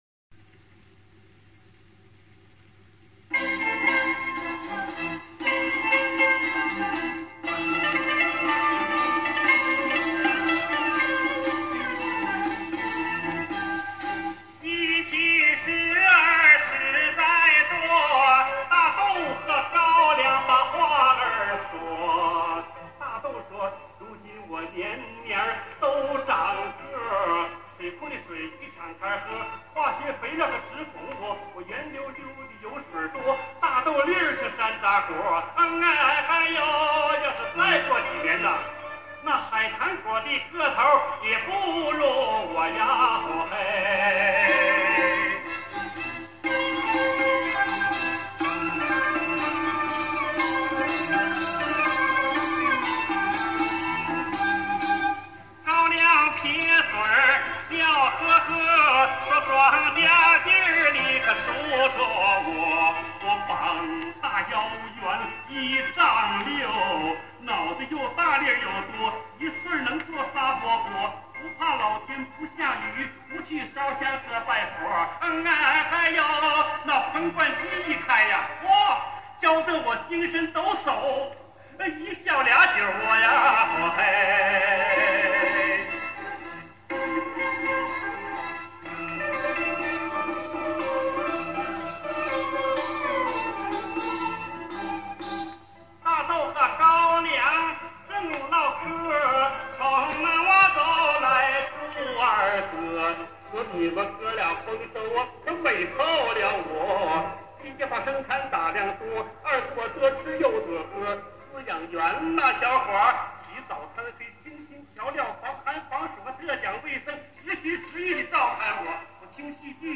东北民歌（夸哈尔滨调）
民乐队伴奏